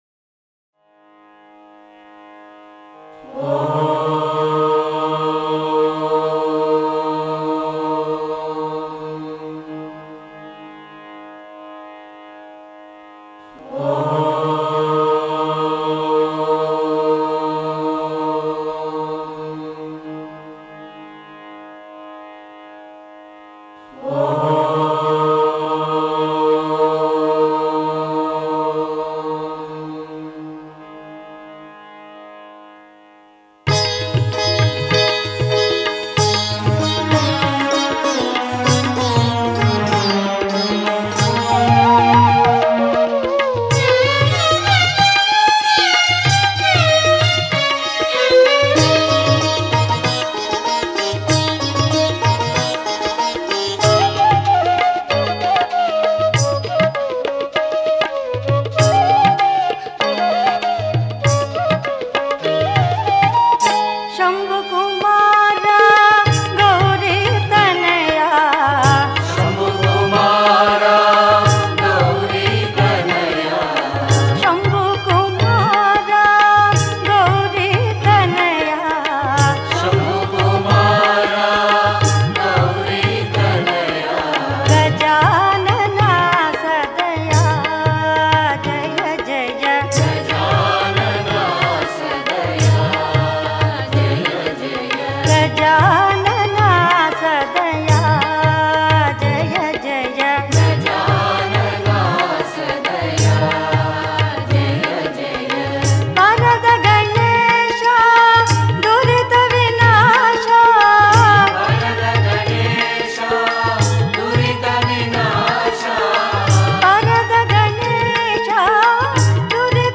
Home | Bhajan | Bhajans on various Deities | Ganesh Bhajans | 51 SHAMBHU KUMARA GOWRI TANAYA